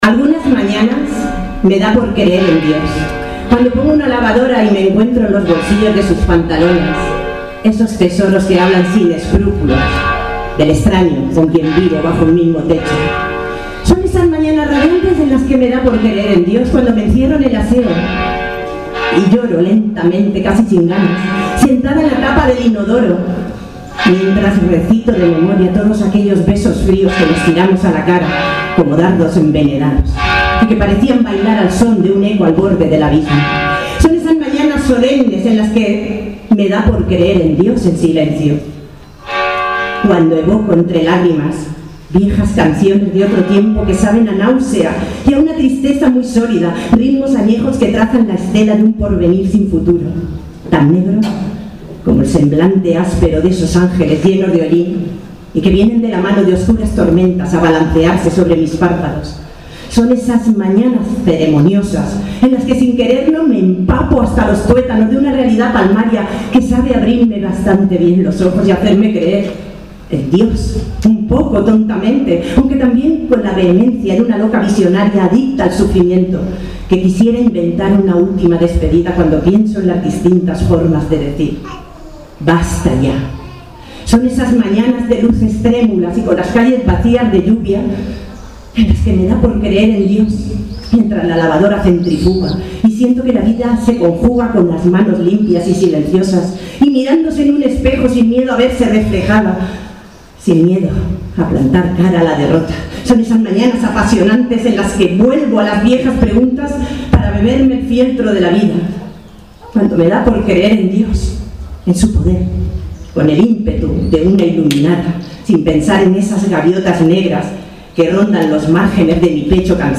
La Casa de Don Pedro ha sido el escenario que ha acogido la gala de entrega de premios del XXVII Certamen Nacional de Poesía Maxi Banegas y el XVI Concurso de Narrativa Corta, en un ambiente íntimo donde la música, la poesía y el vino se dan la mano.